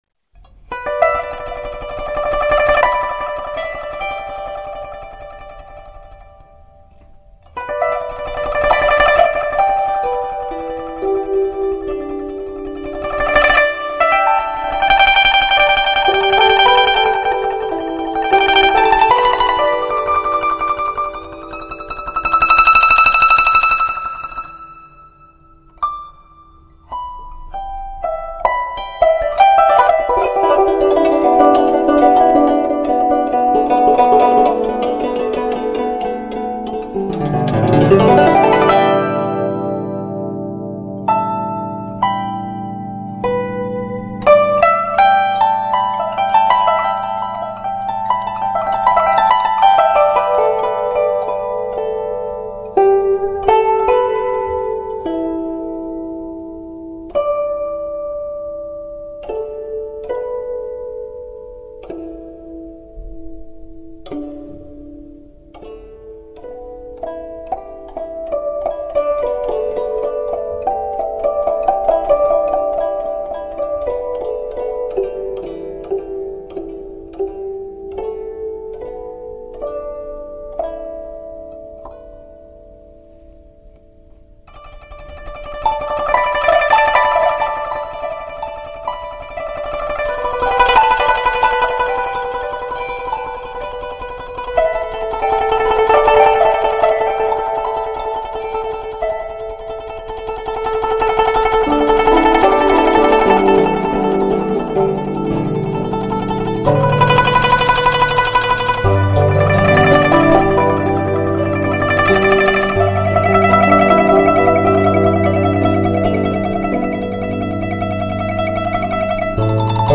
你、我、她 古筝的传说